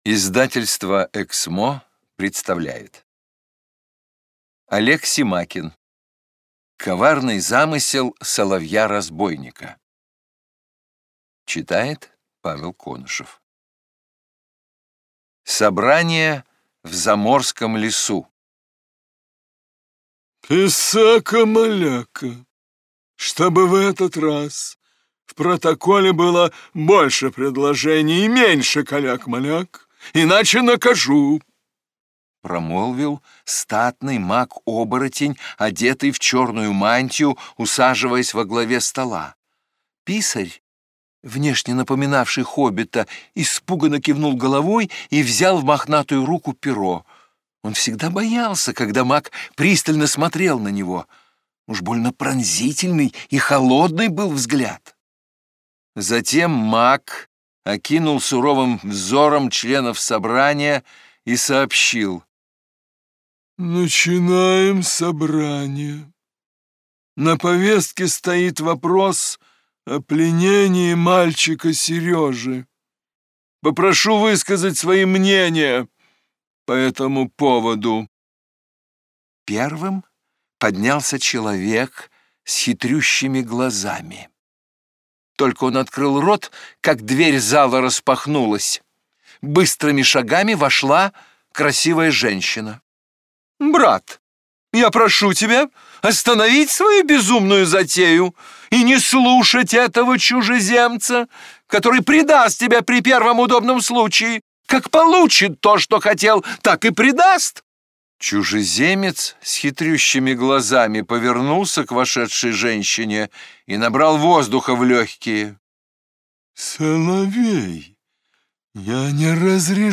Аудиокнига Коварный замысел Соловья-разбойника | Библиотека аудиокниг